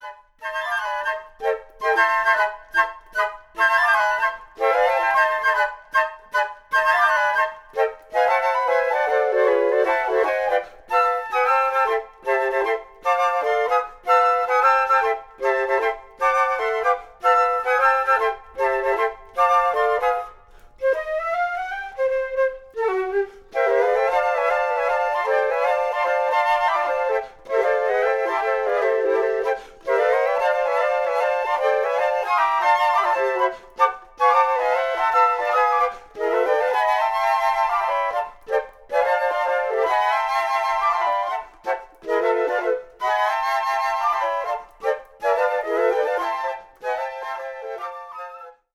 Pour quatuor de flûtes